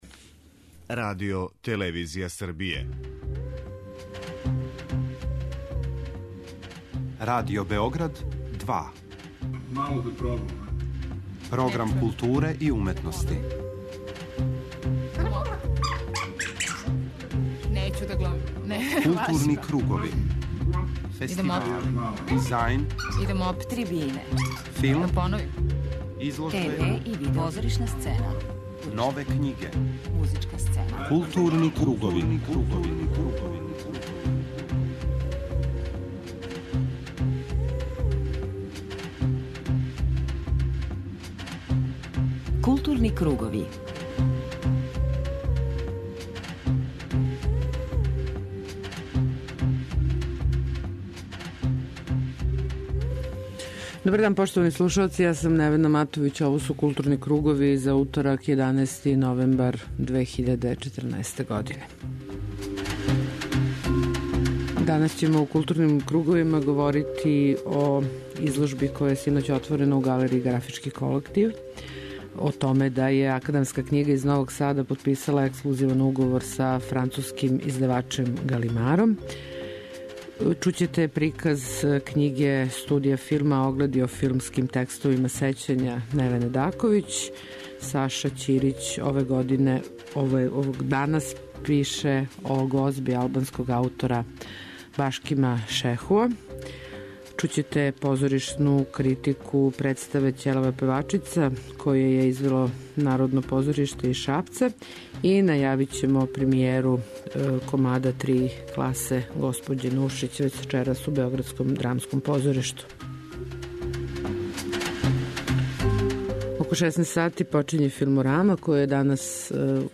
преузми : 54.25 MB Културни кругови Autor: Група аутора Централна културно-уметничка емисија Радио Београда 2.